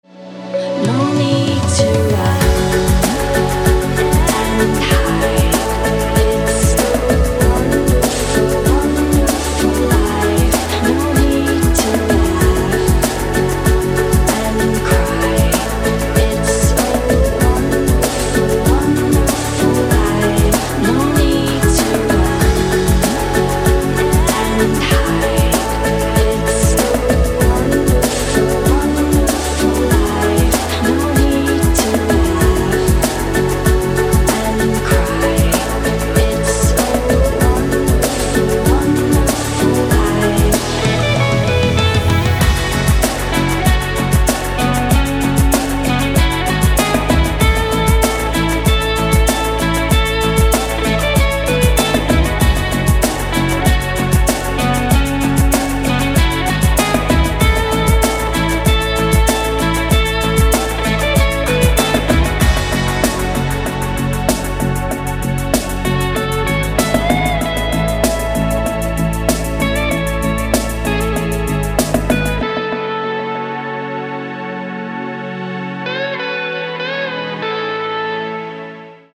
красивые
dance
электронная музыка
спокойные